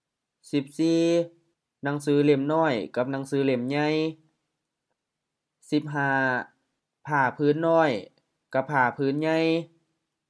Notes: sentence-final: often with rising tone which is likely a Thai influence